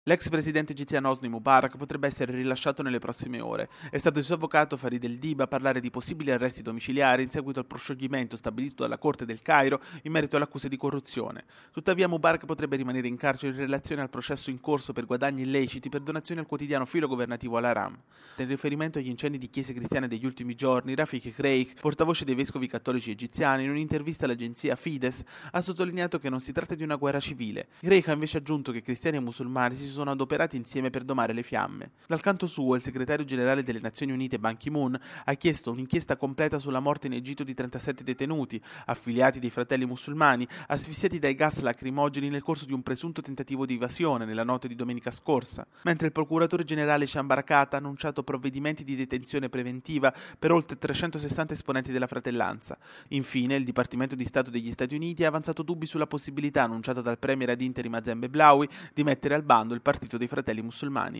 Dal Cairo